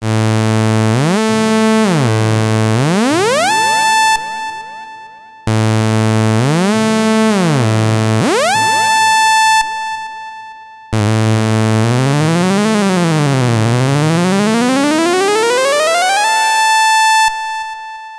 • Gls: glissando: the frequency is sweeped in semitone steps, like if you would strum over the keyboard from one to another note.
Demonstration of the three portamento modes:
mbsidv2_osc_porta.mp3